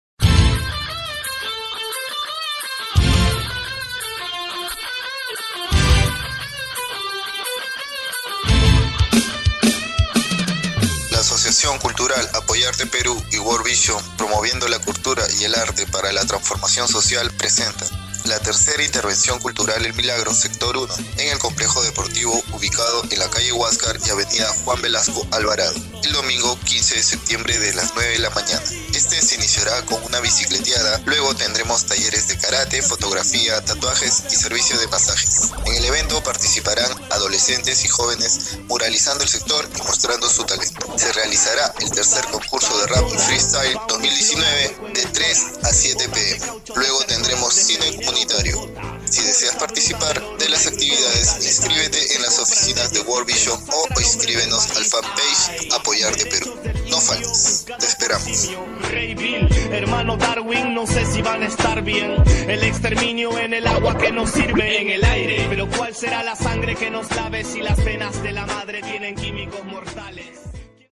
spot-3era-intervención.mp3